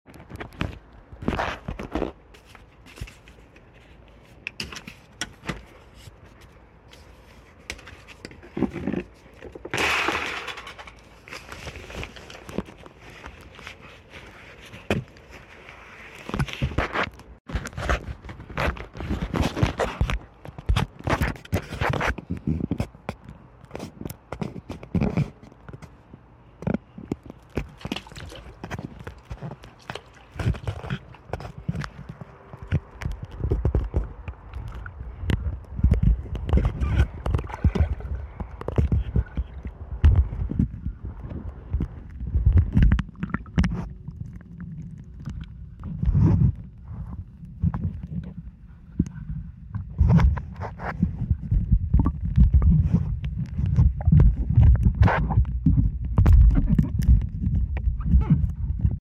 Underwater Sound Effects Free Download
underwater sound effects free download